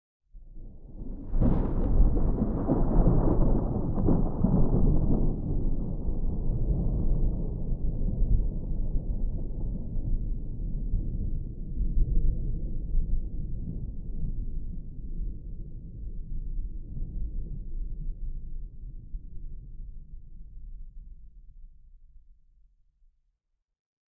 thunderfar_17.ogg